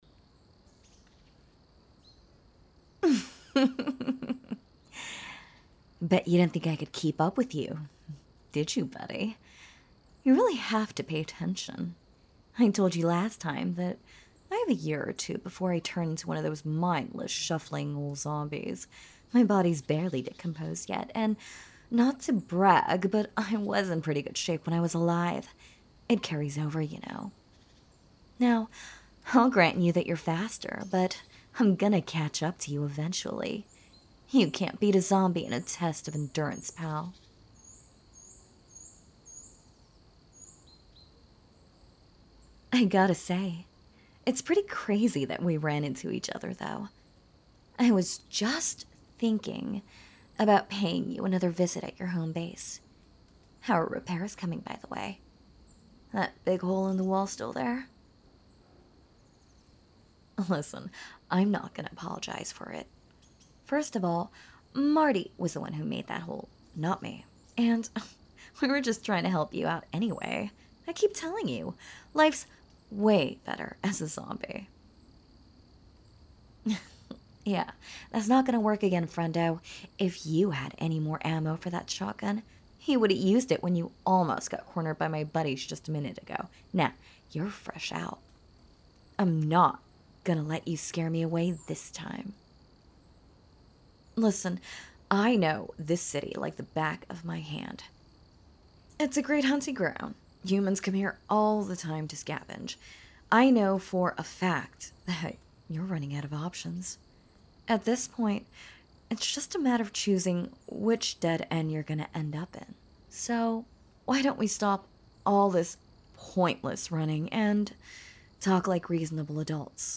And you do an excellent job of capturing the carefree attitude needed to pull this off